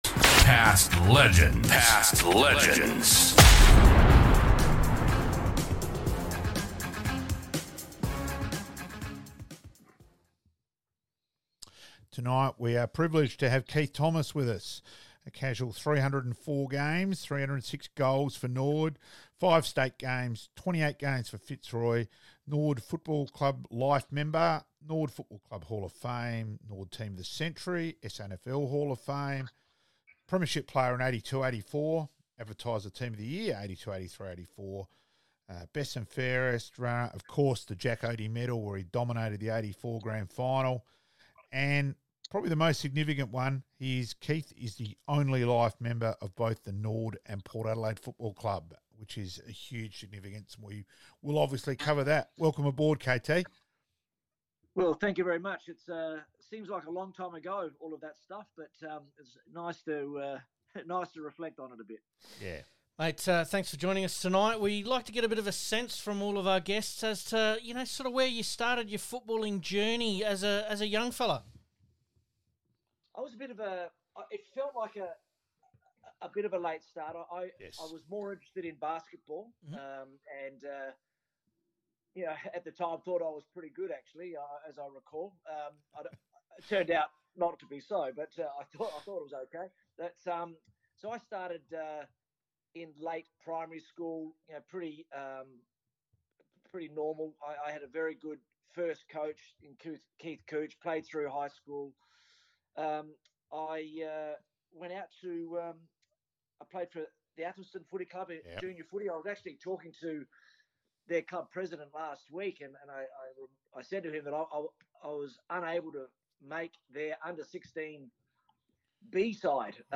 Past Players Past Legends - Interview (only) with some of our special guests